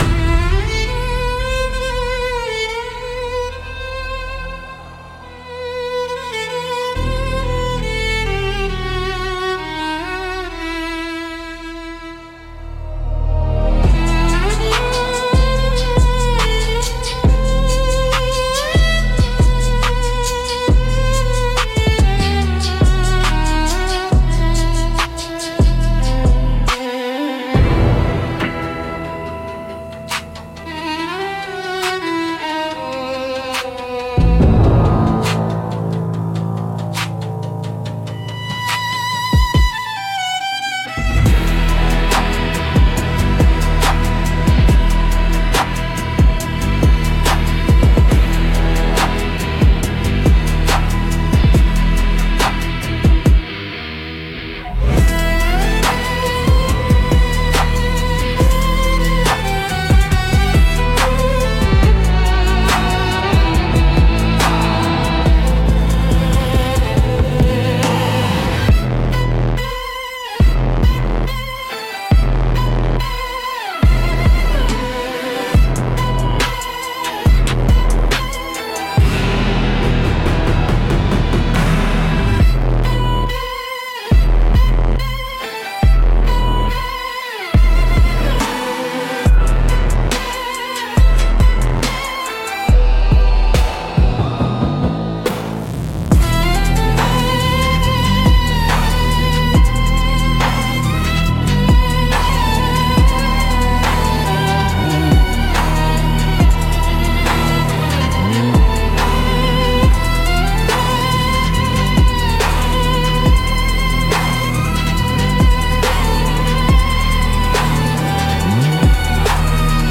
Instrumental - Trap Blues x Gothic Rock Fusion 2.34